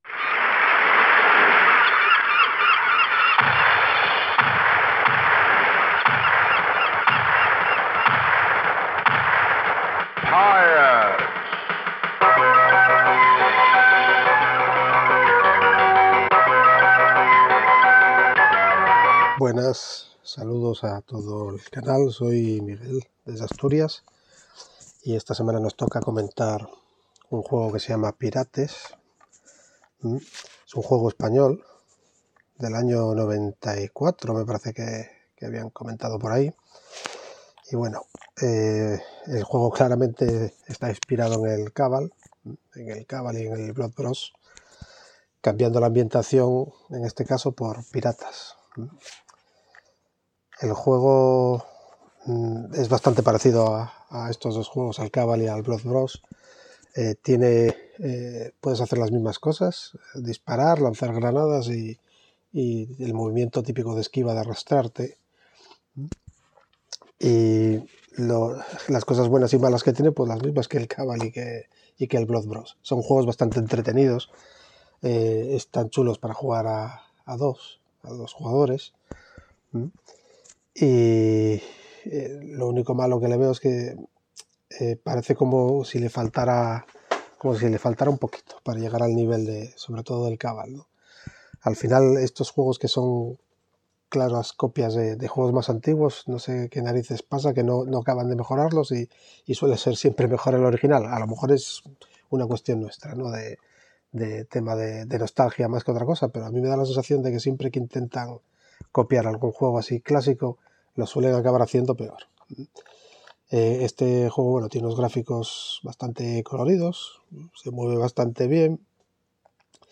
Digo lo que he podido escuchar porque el audio del último participante era horrible, inenarrable, indescriptible.
Pues así se oía el audio del último participante. Lo que vais a escuchar es lo que ha quedado después de un buen puñado de filtros y herramientas de IA.